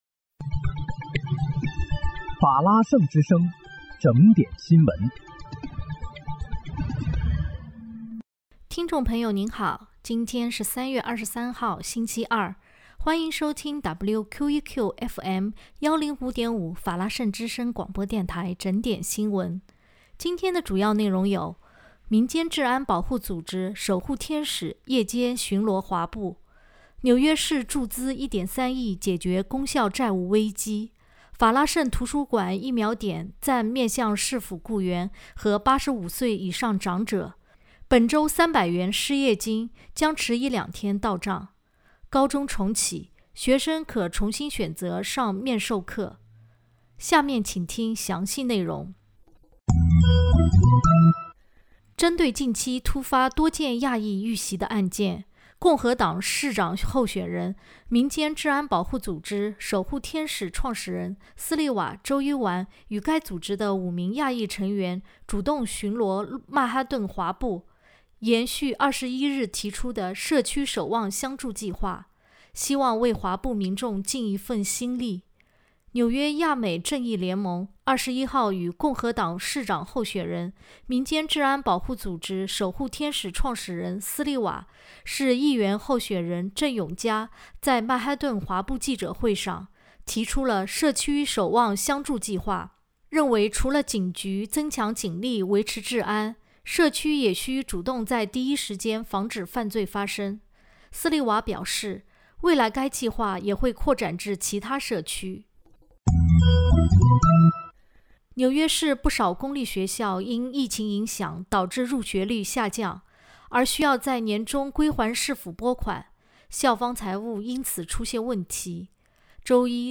3月23日（星期二）纽约整点新闻